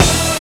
HIT BRASSOID.wav